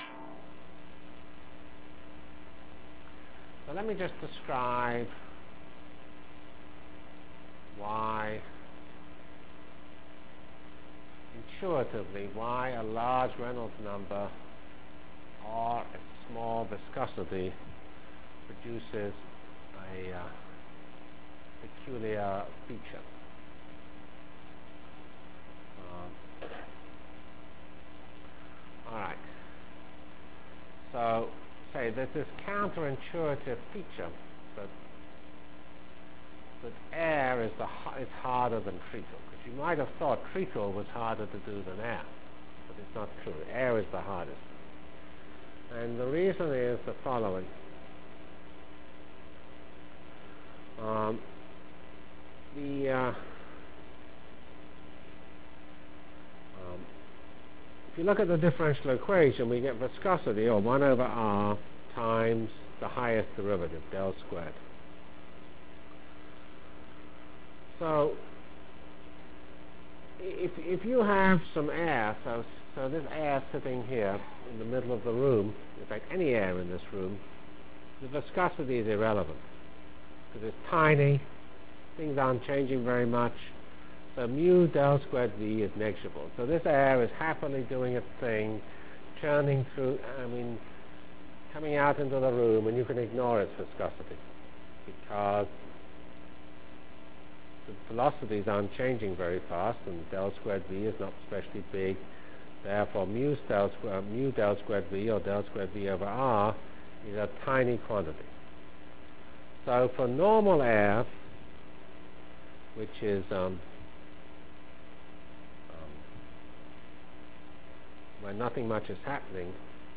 Delivered Lectures of CPS615 Basic Simulation Track for Computational Science -- 14 November 96.